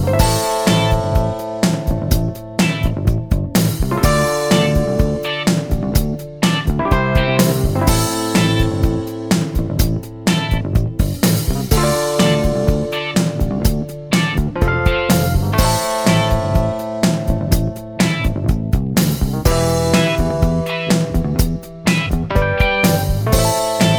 Minus Sax Pop (1980s) 3:57 Buy £1.50